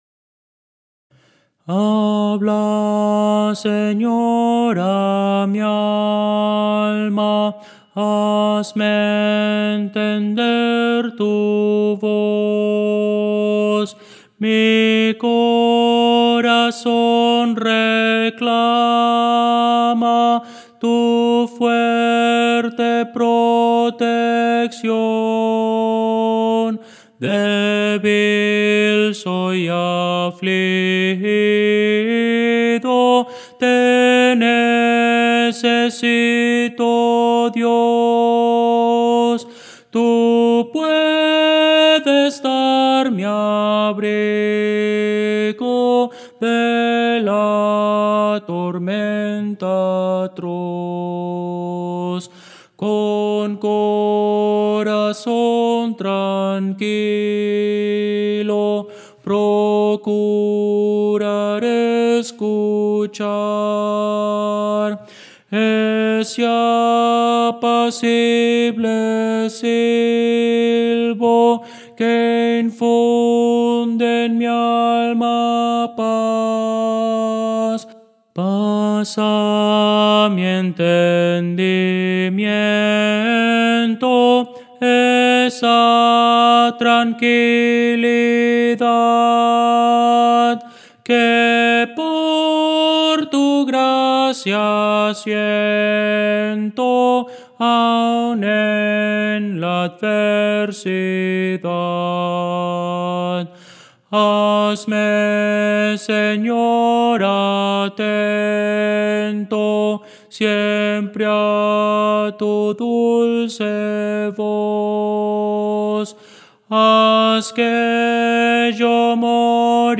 Música: MIDI
Voces para coro